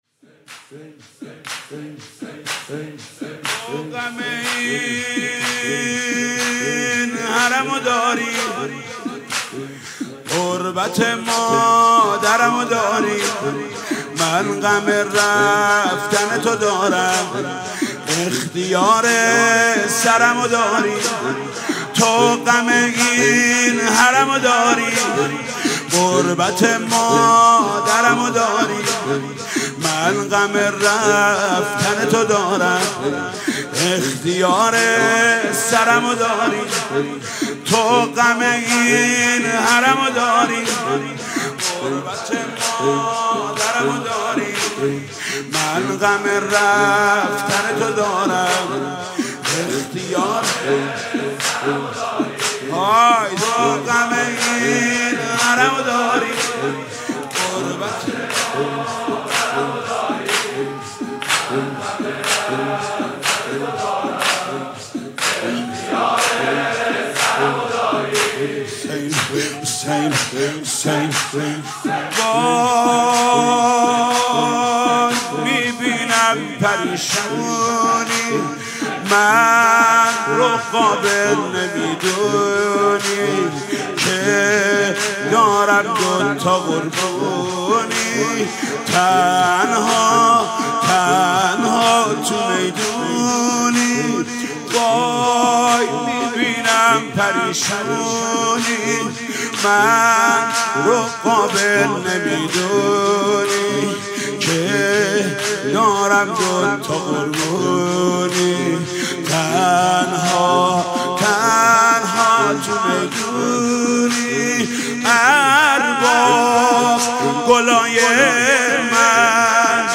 دانلود مداحی پیاده روی اربعین محمود کریمی تو غم این حرم داری غربت مادرم داری - زمینه